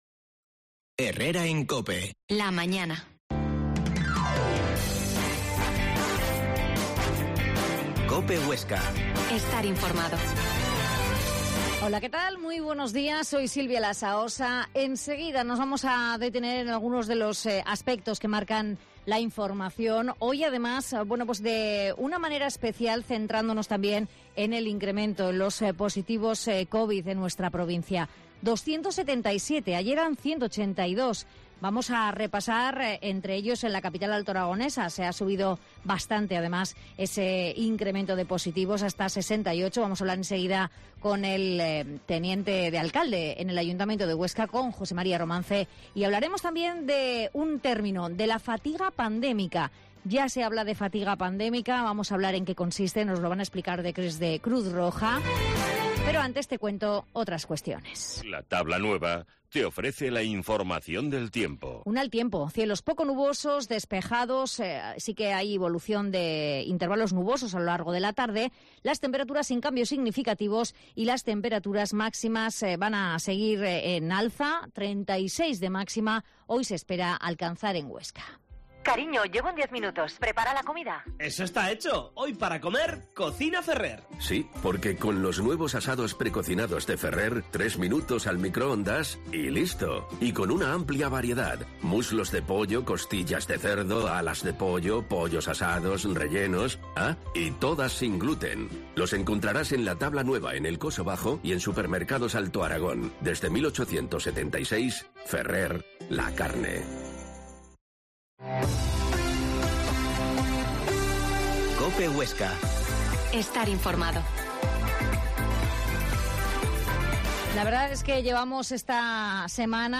Herrera en COPE Huesca 12.50h. Entrevista al Teniente de Alcalde de Huesca, José María Romance